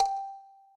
kalimba_g.ogg